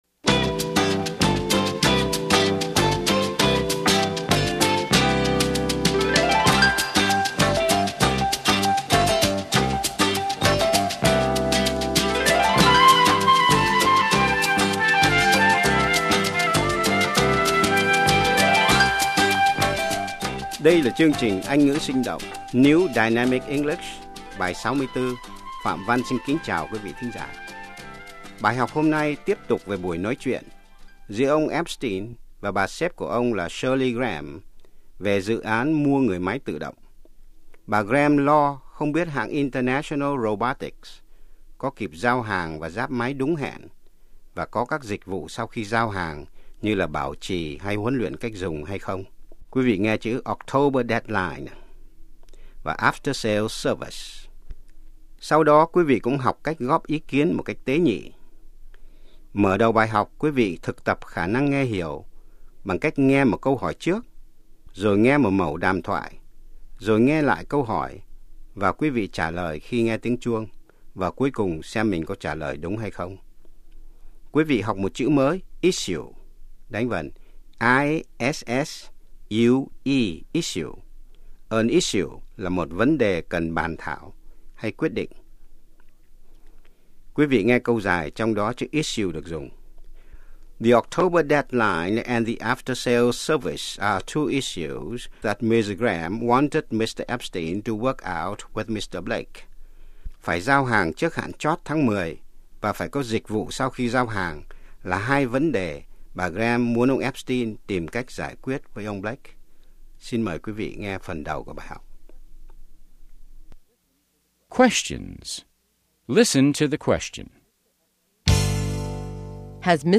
Mở đầu bài học, quí vị thực tập khả năng nghe hiểu bằng cách nghe một câu hỏi trước, rồi nghe một mẩu đàm thoại, rồi nghe lại câu hỏi, và quí-vị trả lời khi nghe tiếng chuông, và cuối cùng xem mình có trả lời đúng hay không.